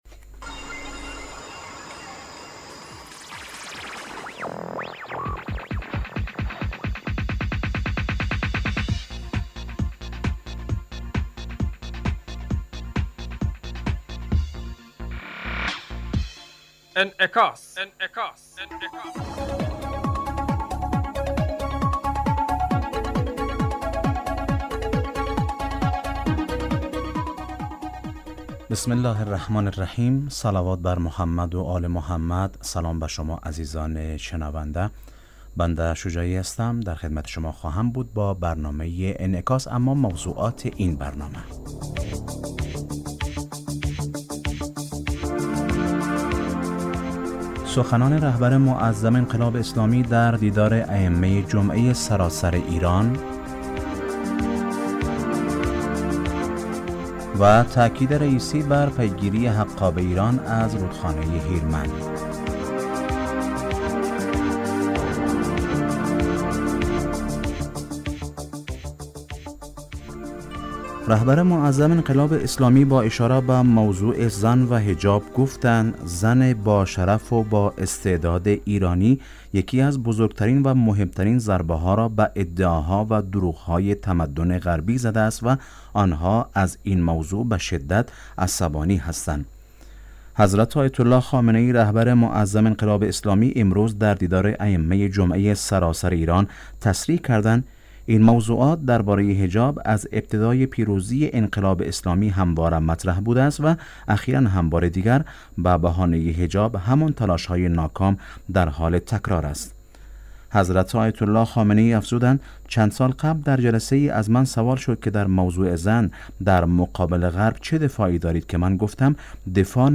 برنامه انعکاس به مدت 35 دقیقه هر روز در ساعت 18:50 بعد ظهر بصورت زنده پخش می شود. این برنامه به انعکاس رویدادهای سیاسی، فرهنگی، اقتصادی و اجتماعی مربوط به افغانستان و تحلیل این رویدادها می پردازد.